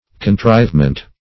Search Result for " contrivement" : The Collaborative International Dictionary of English v.0.48: Contrivement \Con*trive"ment\, n. Contrivance; invention; arrangement; design; plan.